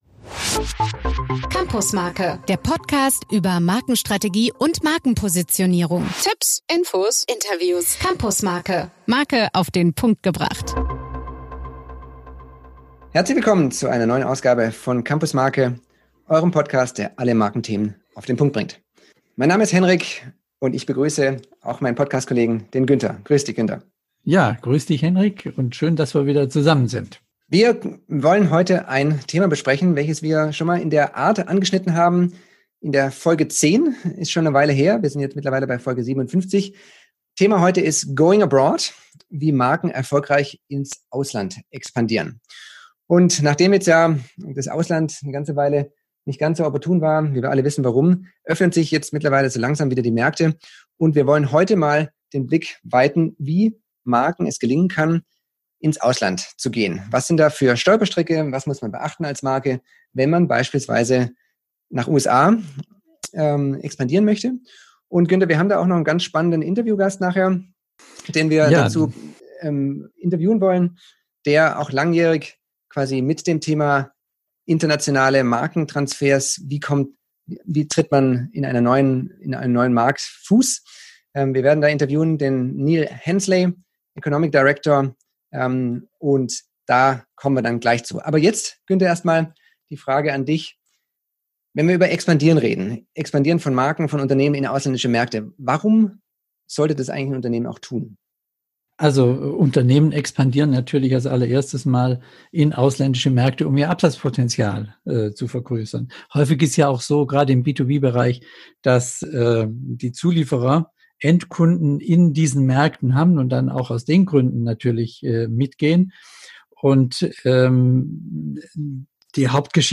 Seine Erfahrungen wird er mit uns teilen (das Interview ist auf Englisch).